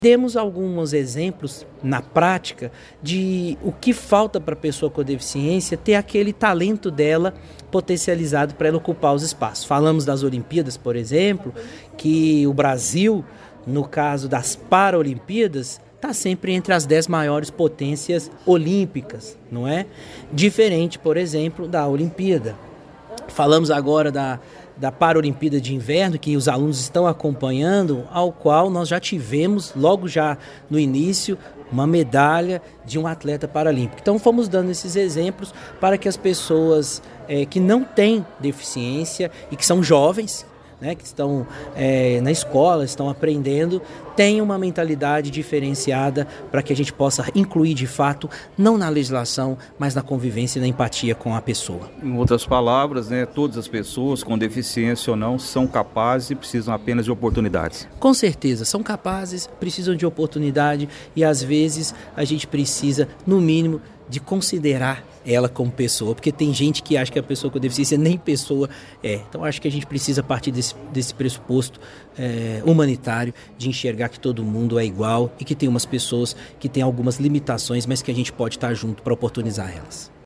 Palestra reforça reflexão sobre igualdade e respeito